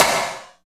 47.10 SNR.wav